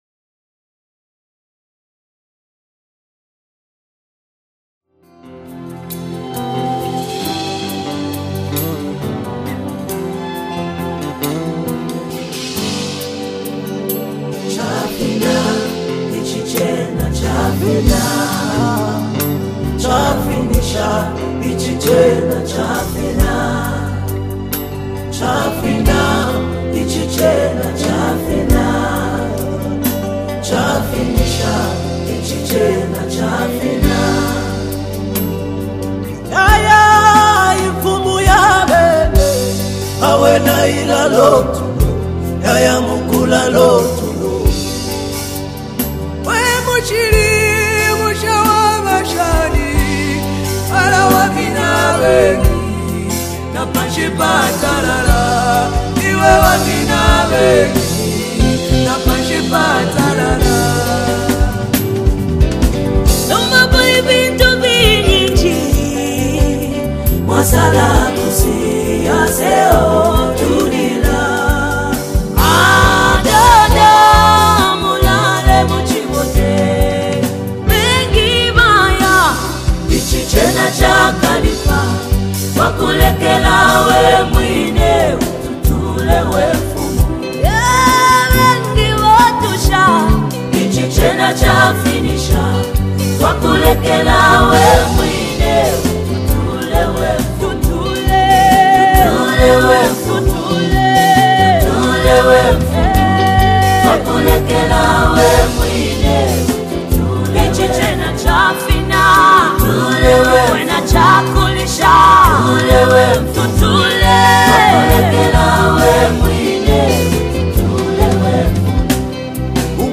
ZAMBIAN GOSPEL GROUP
emotionally charged and spiritually uplifting track
Through stirring vocals and poetic lyrics